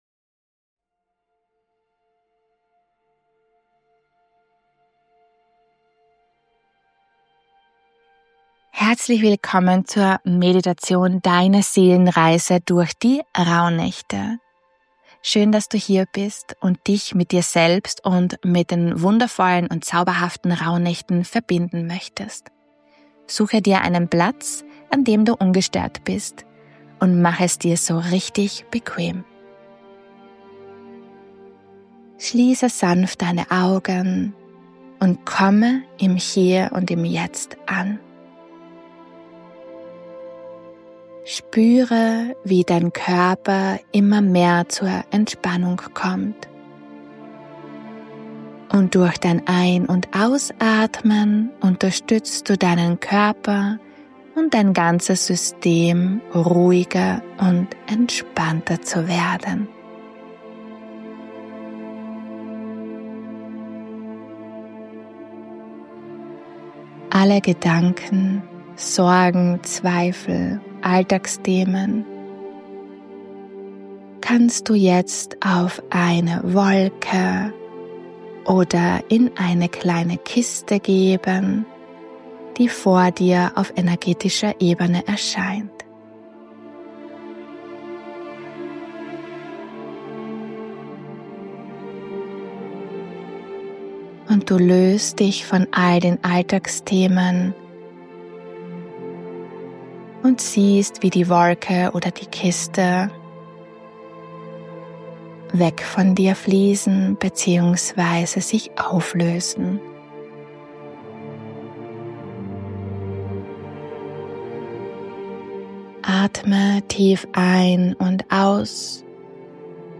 Diese Rauhnachtsmeditation guided dich durch deine Seelenräume & zu deinen Botschaften in den 12 Rauhnächten. Eine Seelenreise durch Möglichkeiten, Potenziale, aber auch Reinigung und Heilung.